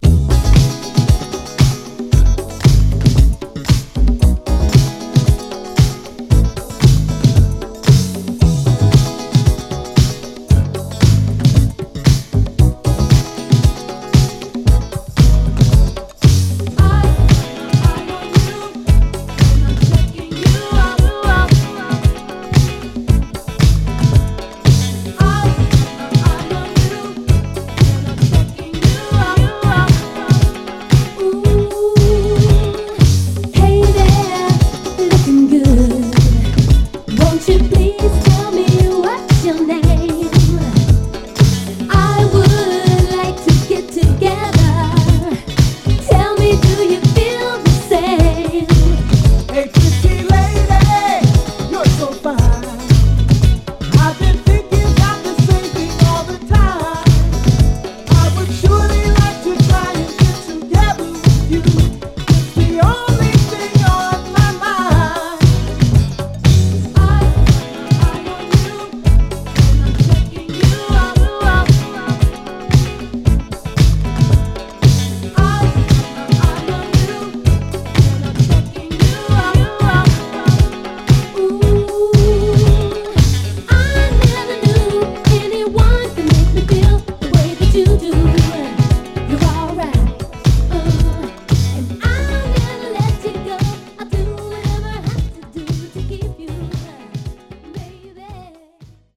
フロア映えするブギー・ファンクに瑞々しいヴォーカルが映える
※試聴音源は実際にお送りする商品から録音したものです※